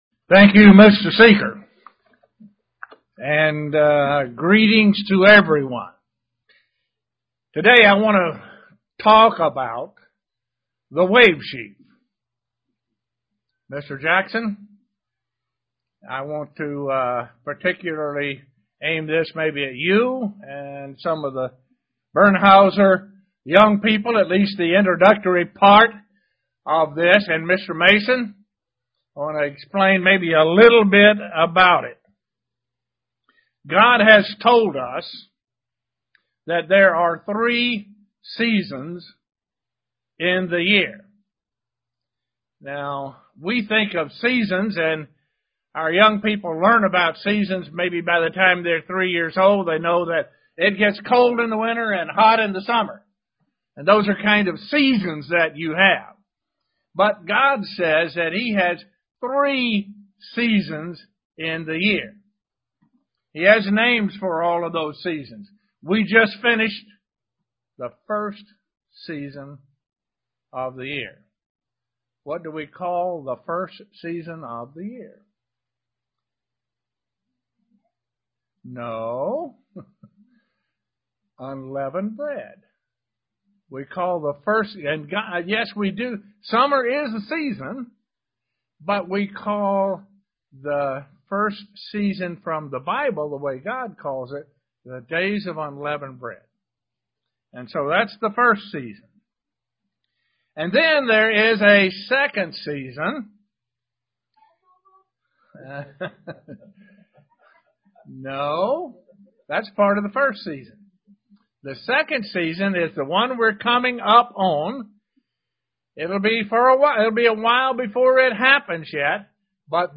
Given in Elmira, NY
Print What the wave sheaf symbolizes UCG Sermon Studying the bible?